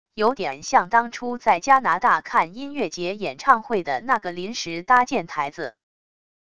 有点像当初在加拿大看音乐节演唱会的那个临时搭建台子wav音频